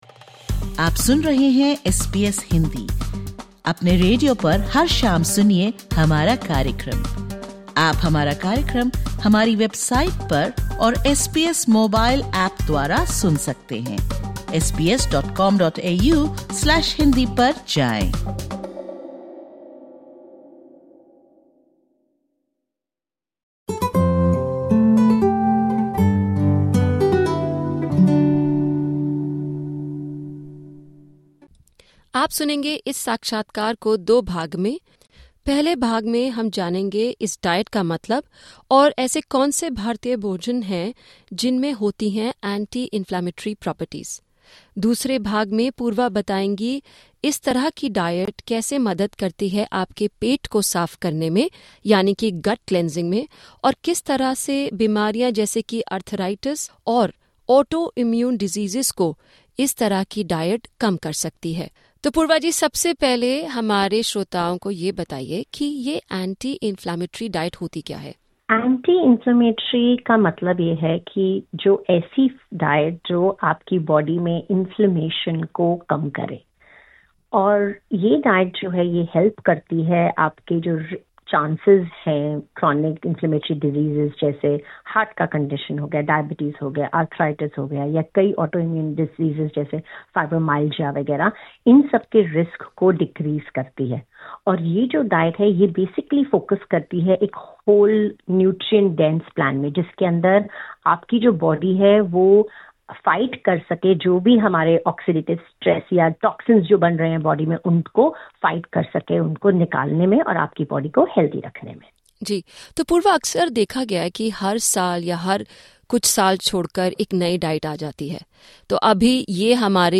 Every year, a new diet trend takes the spotlight—from Keto and Paleo to Gluten-Free, and now, the anti-inflammatory diet. In this podcast, SBS Hindi speaks with an expert to uncover the real benefits of this diet, explore foods that help combat inflammation, highlight essential Indian ingredients, and discuss its potential role in managing chronic conditions like arthritis and autoimmune diseases.